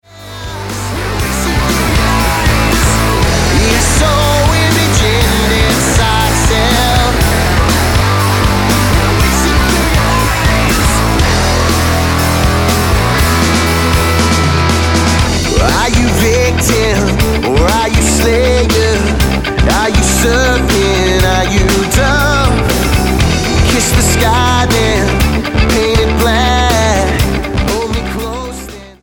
UK rock band
Style: Rock